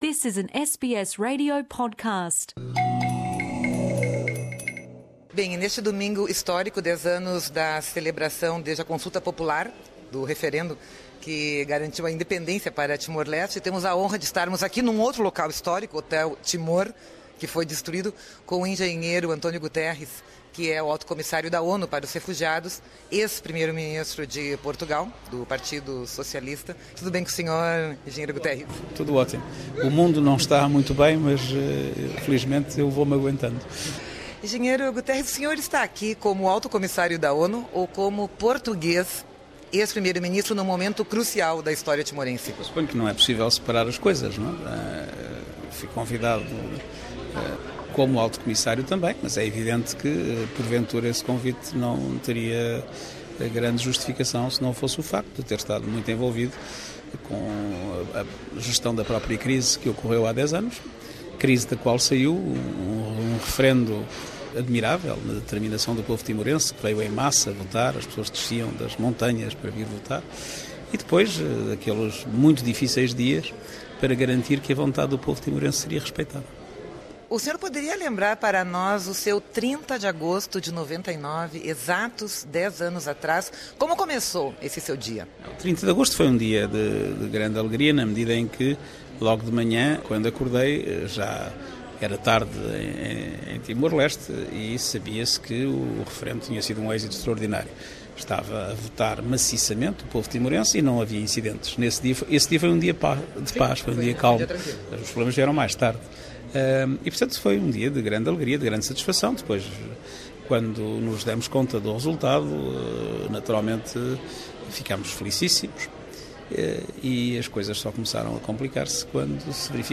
Entrevista do então Alto-Comissário da ONU para os Refugiados/ACNUR, em Díli, à Radio SBS, no dia 30 de agosto de 2009, quando António Guterres participava da festa de 10 anos do referendo da independência de Timor-Leste.